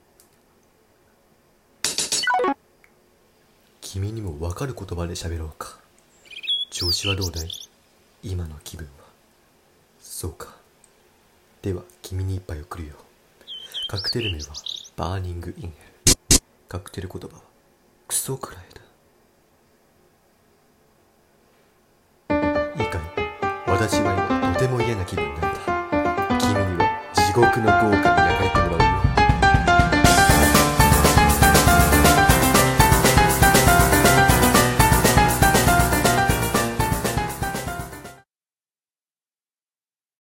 【台詞】最後の回廊にいたのは？【Undertale】